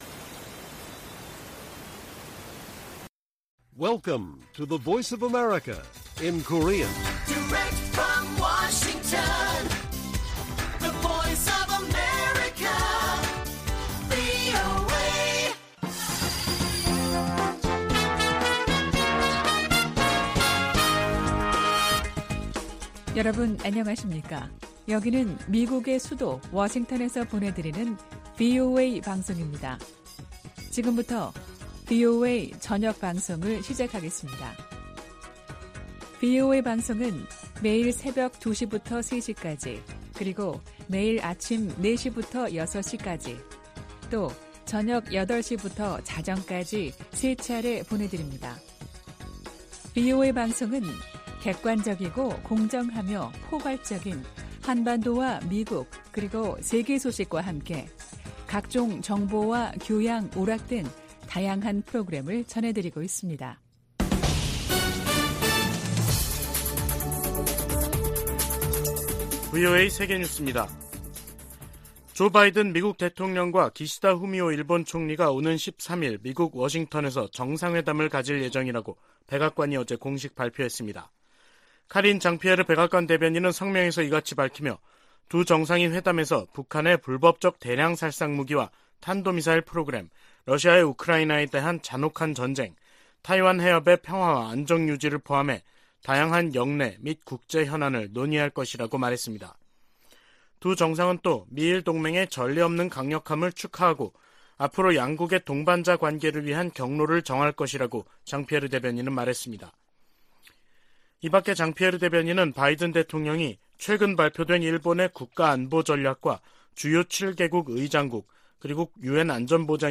VOA 한국어 간판 뉴스 프로그램 '뉴스 투데이', 2023년 1월 4일 1부 방송입니다. 미국 백악관은 미국과 한국 두 나라가 북한 핵 사용 가능성에 대한 대응 방안을 논의 중이라고 확인했습니다. 윤석열 한국 대통령은 북한이 다시 한국 영토를 침범할 경우 9.19 남북 군사합의 효력 정지를 검토하라고 지시했습니다.